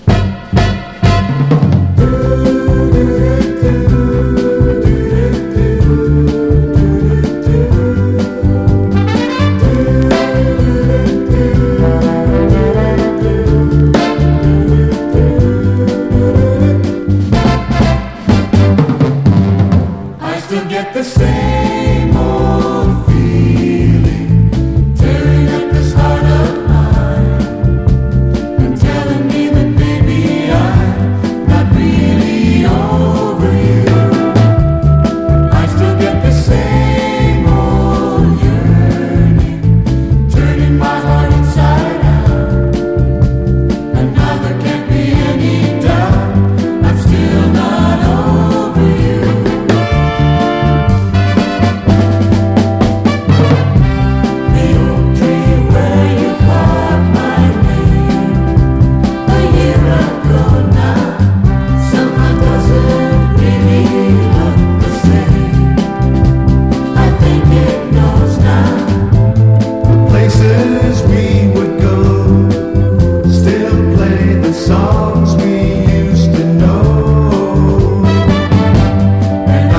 ROCK / 60'S / SAMPLING SOURCE / DRUM BREAK